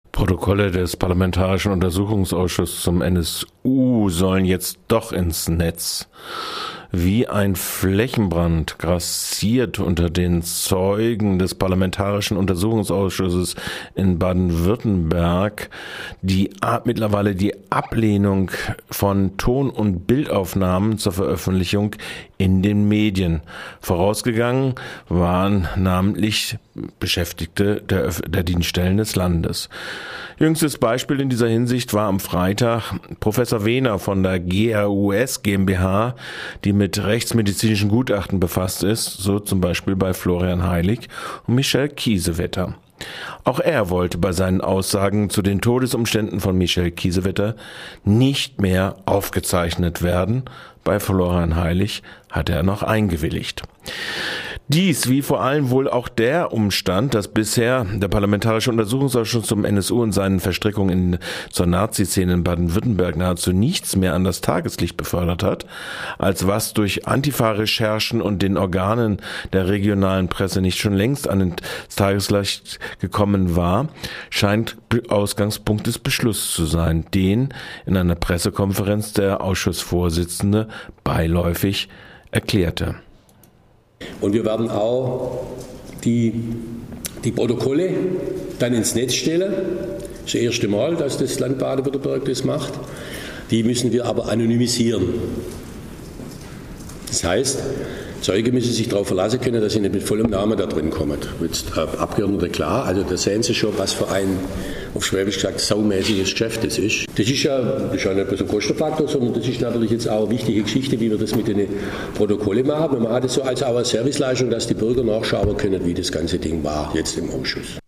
Bericht unseres Korrespondenten von der 28 Sitzung des parlamentarischen Untersuchungsausschuss zum NSU in Baden-Württemberg.